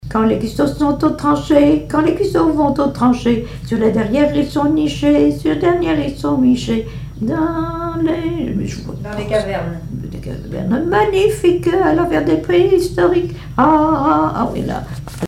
timbre de Cadet Roussel
Témoignages et chansons
Pièce musicale inédite